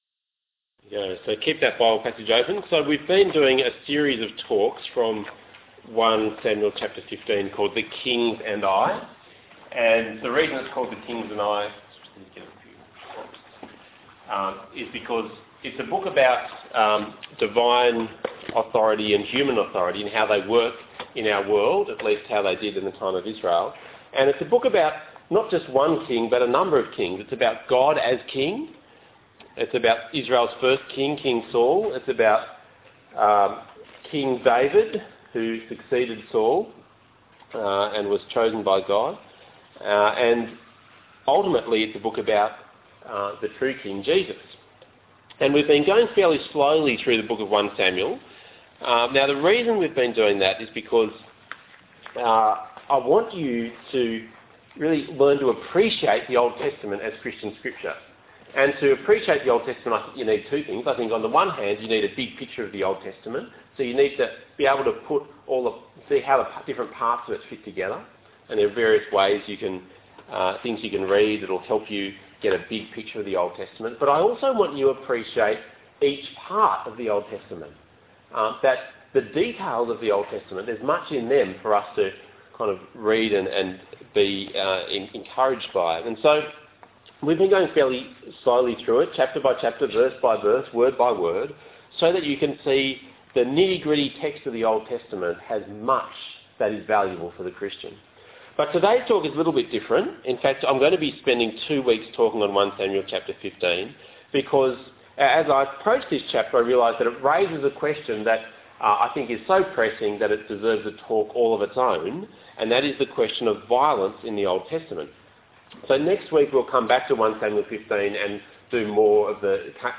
Talk Type: Bible Talk